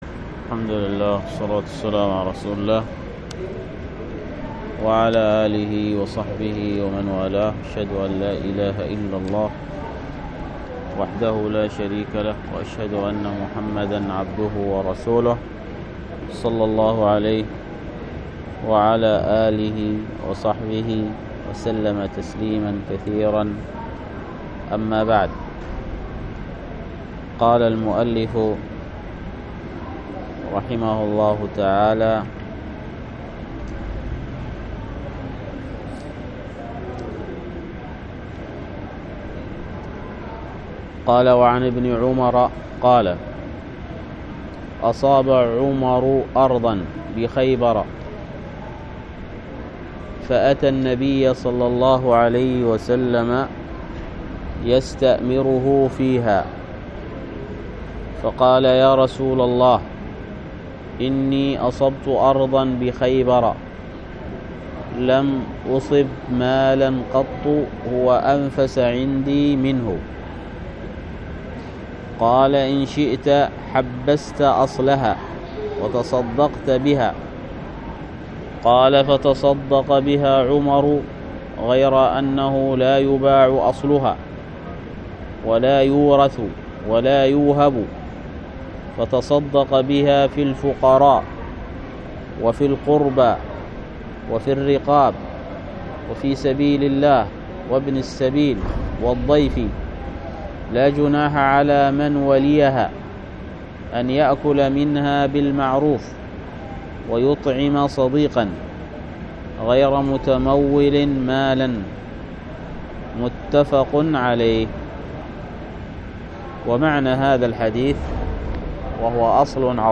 الدرس في كتاب البيوع من فتح المعين في تقريب منهج السالكين 42